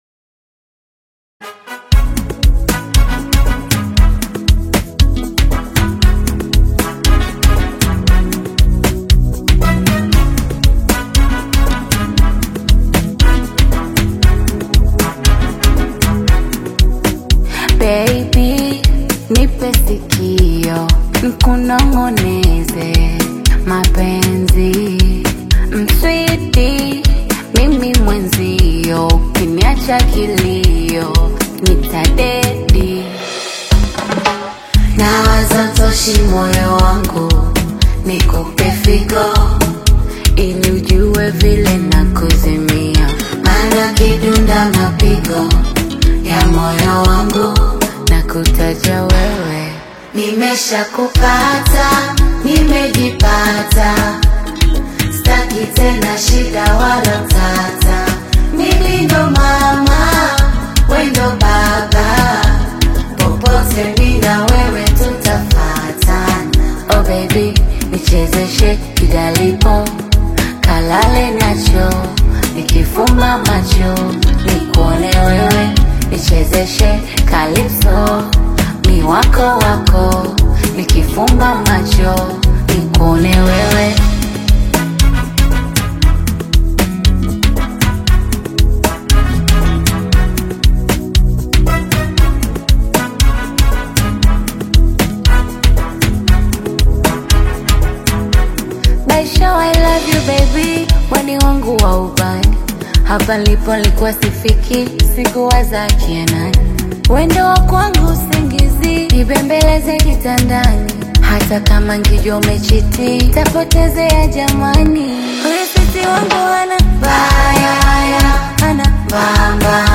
a mid-tempo groove